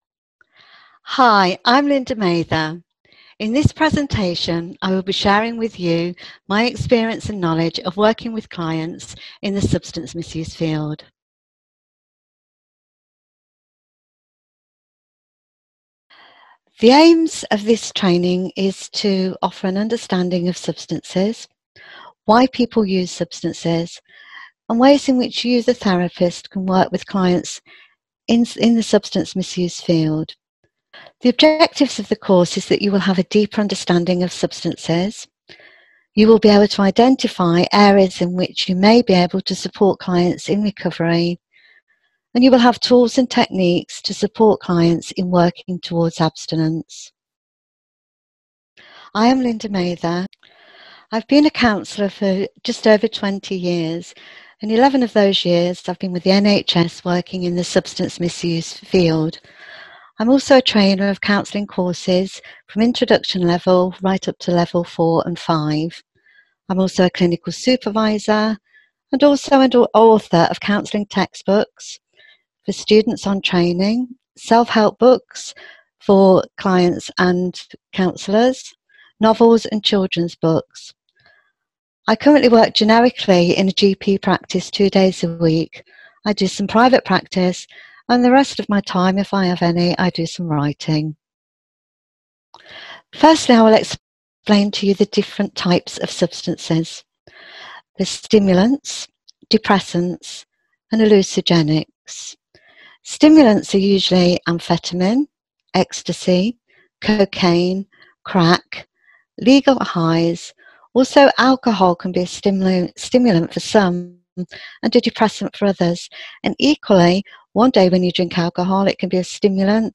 Lecture is Now Unlocked